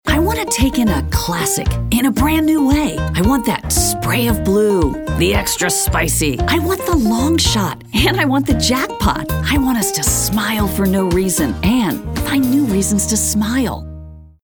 confident, conversational, friendly, genuine, middle-age, motivational, perky, soccer mom, upbeat